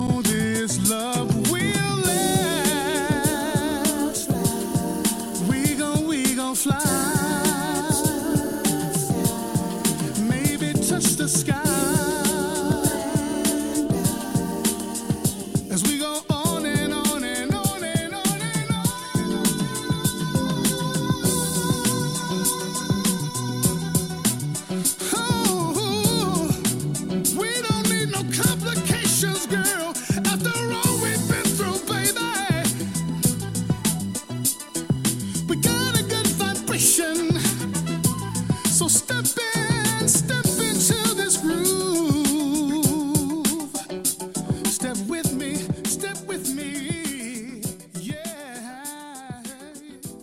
trademark soaring vocals